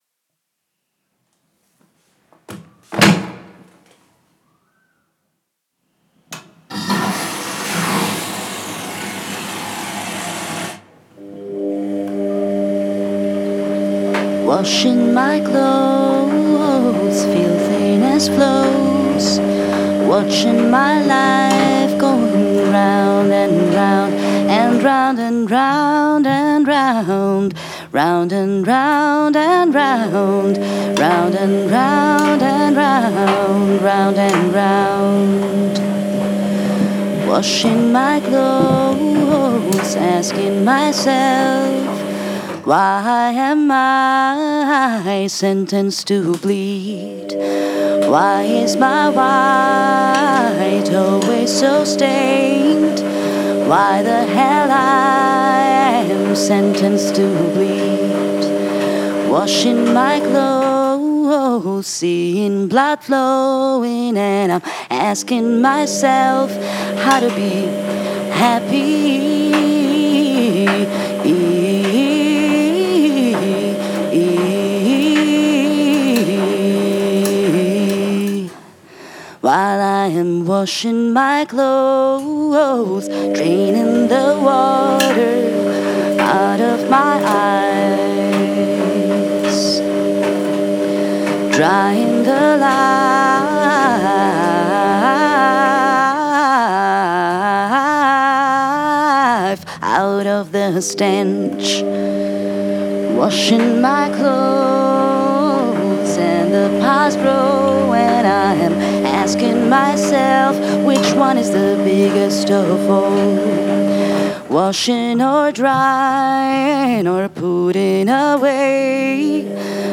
The Washing Machine
Vacuum Cleaner Dirt Devil
Sewing Machine Deutsche Wertarbeit